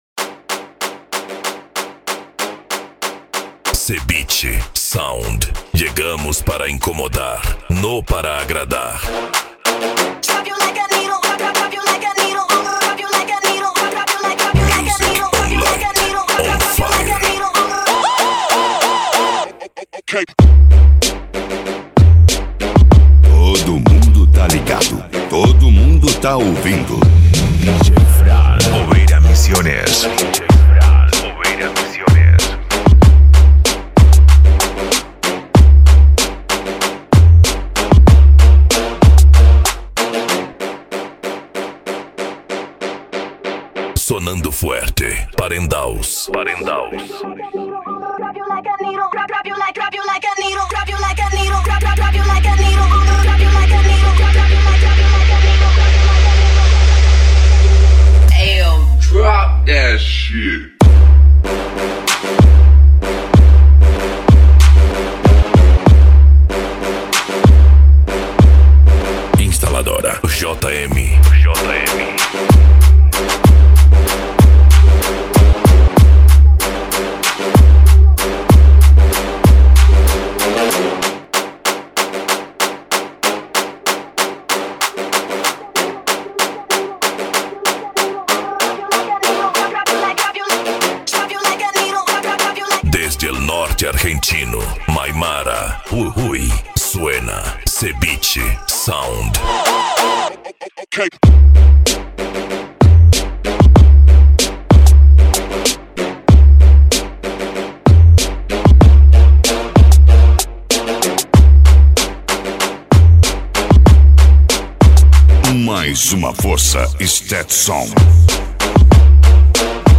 Remix
Racha De Som
Bass